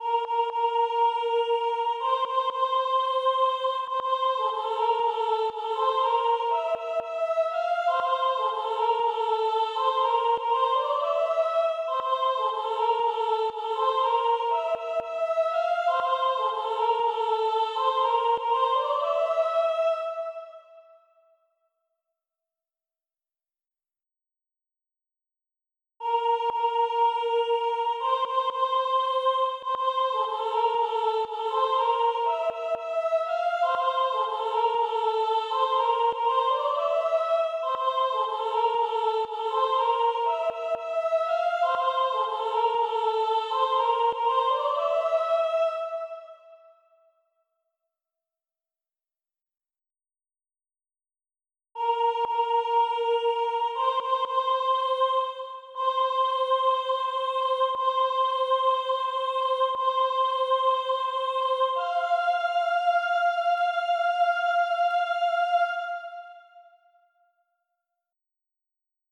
Soprano  - HAH voix Sp